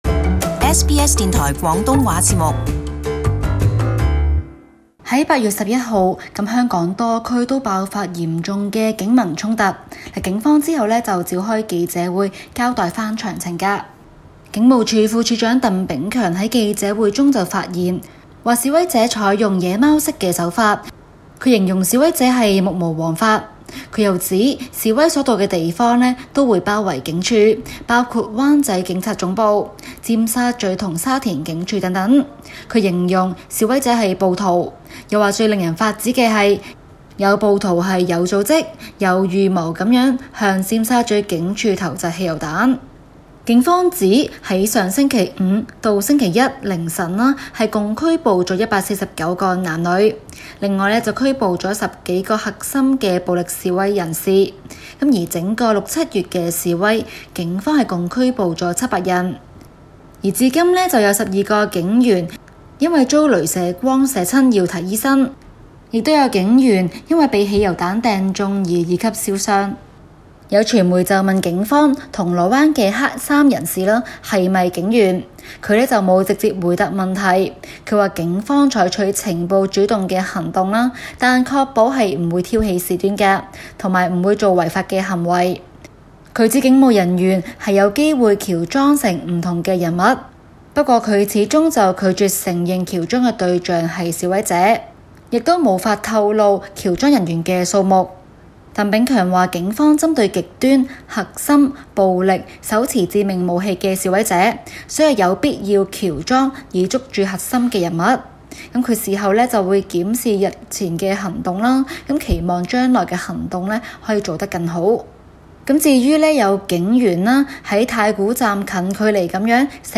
香港警方昨日舉行記者會，警務處派出多名要員，包括副處長 (行動) 鄧炳強解答記者問題。